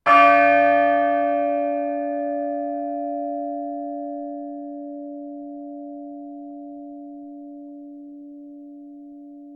Church Bells